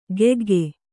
♪ gegge